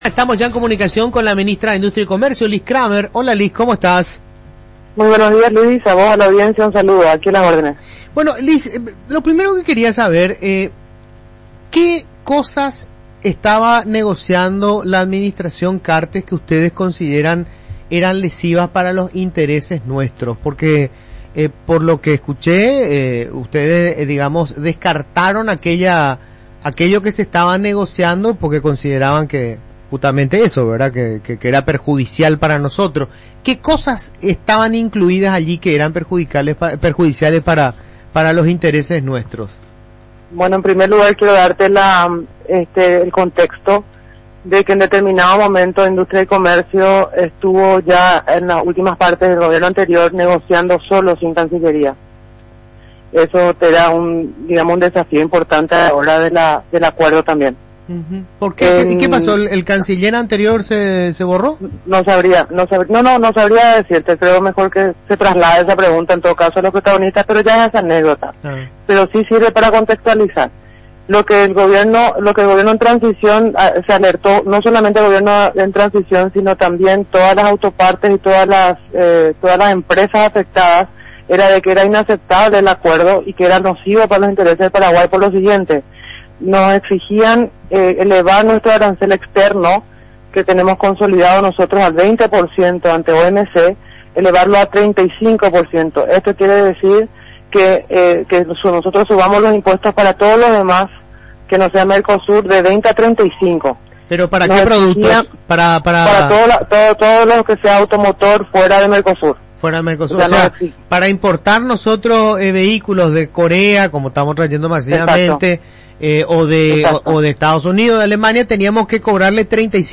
La ministra de Industria y Comercio Liz Cramer habla sobre las medidas inmediatas y a mediano plazo que el gobierno piensa presentar ante la aplicación de aranceles aduaneros por parte del Brasil a las autopartes fabricadas en Paraguay.